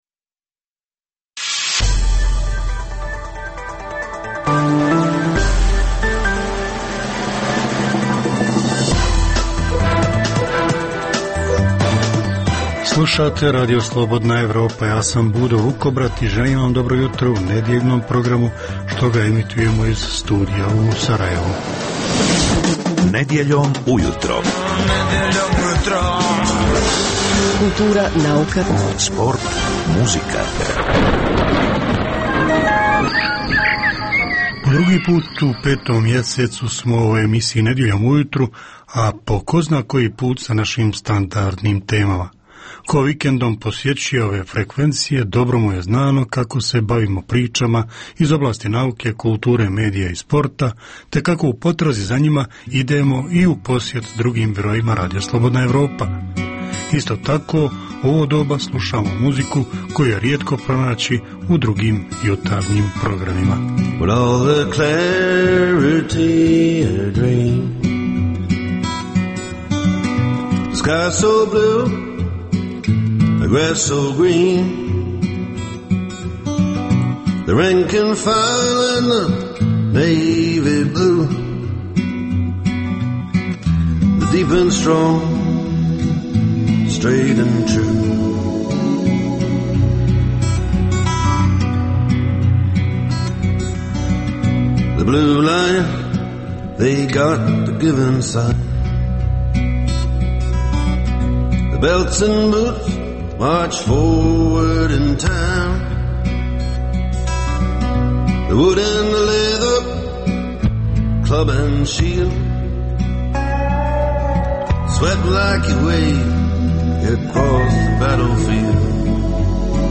Poslušajte intervju sa zanimljivim gostom i, uz vijesti i muziku, pregled novosti iz nauke i tehnike, te čujte šta su nam pripremili novinari RSE iz regiona.